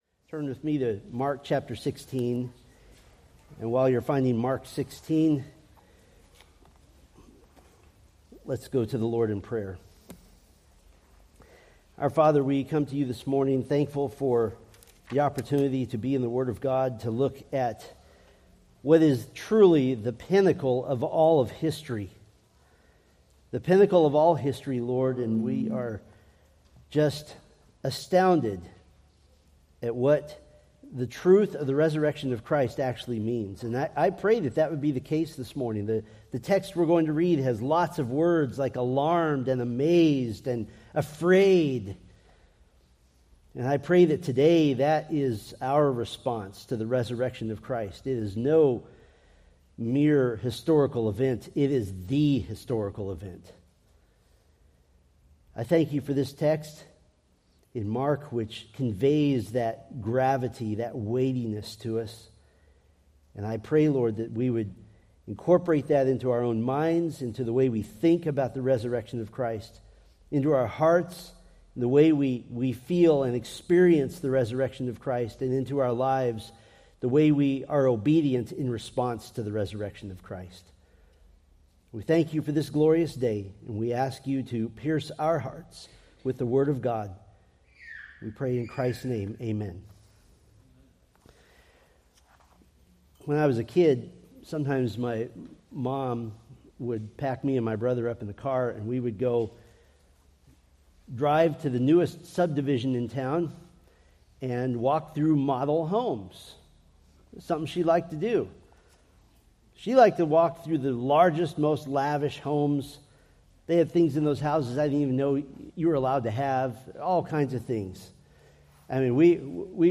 Preached April 20, 2025 from Mark 16:1-8